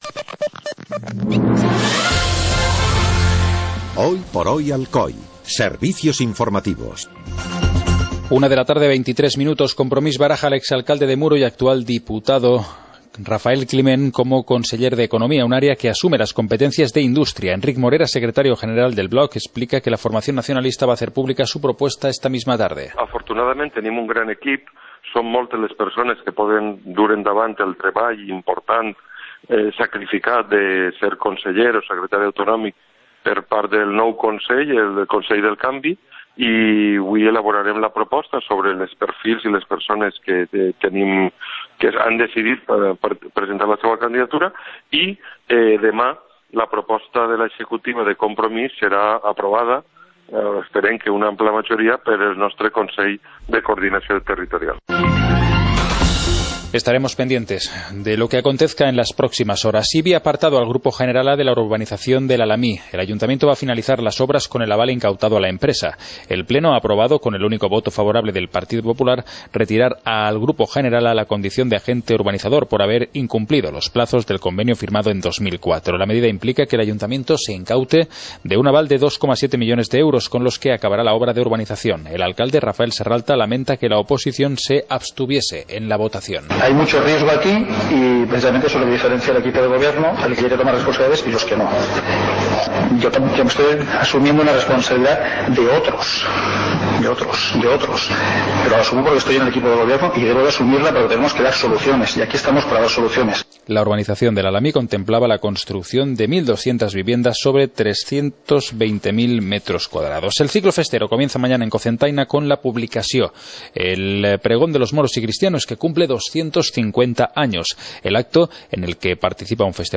Informativo comarcal - viernes, 26 de junio de 2015